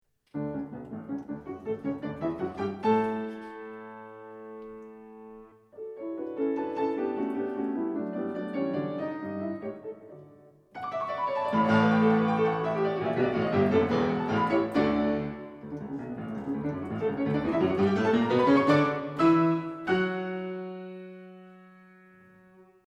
It’s quite a an exciting beginning, full of energy and life.